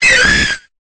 Cri de Corayon dans Pokémon Épée et Bouclier.